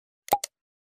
Звук с подпиской на YouTube